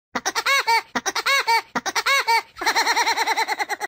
Звук смеха дятла Вуди в мультике